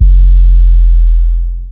808 - XO.wav